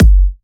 VFH2 128BPM Capone Kick.wav